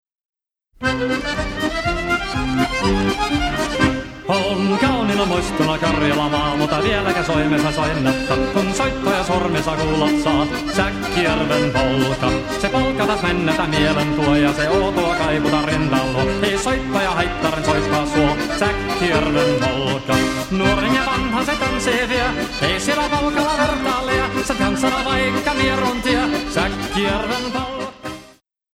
accordion
drums and vocals